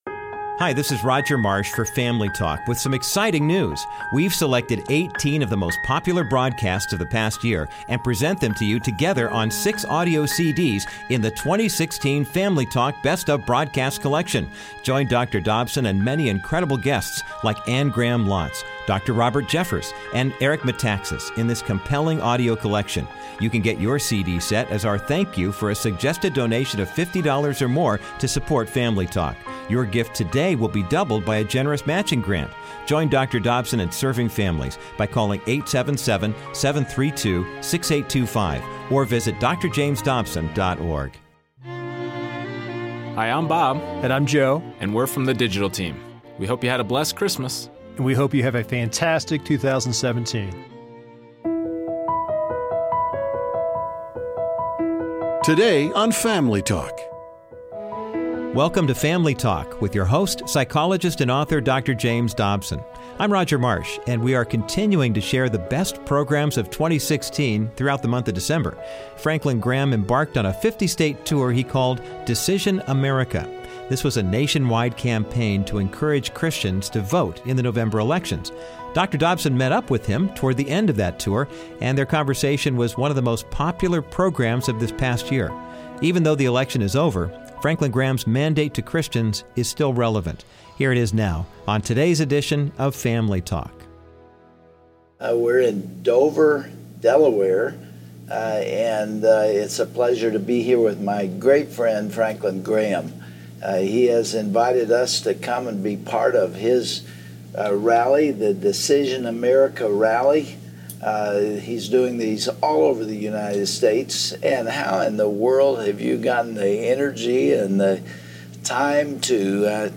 As the tour ended, he shared his thoughts about the Christians role in the political process. Hear his conversation with Dr. Dobson today on Family Talk.